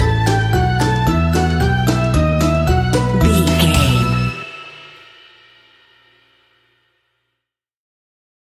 Aeolian/Minor
childrens music
instrumentals
fun
childlike
cute
happy
kids piano